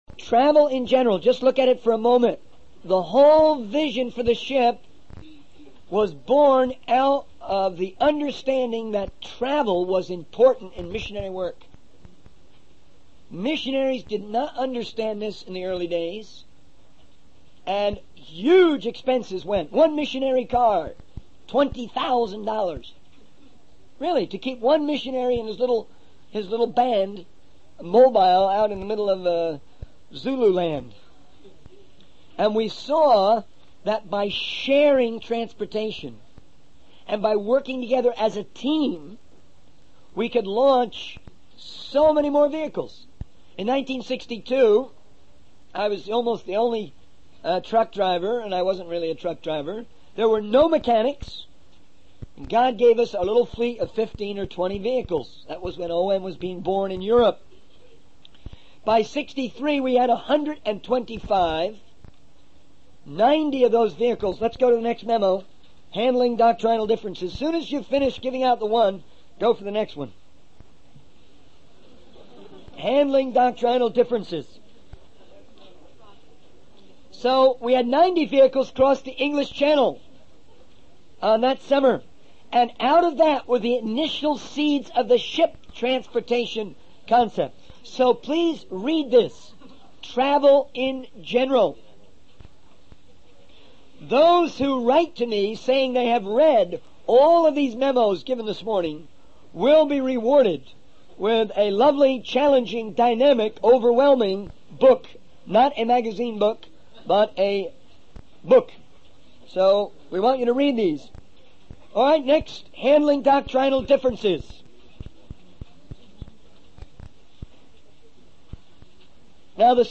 The sermon touches on the topic of handling doctrinal differences and the significance of understanding present trends in the British Church. The speaker encourages the audience to take notes and highlights the importance of history in the Bible.